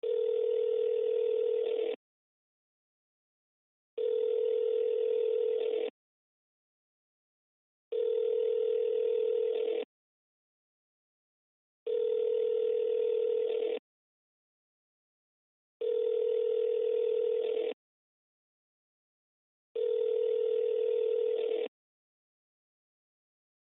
ringoutgoing.mp3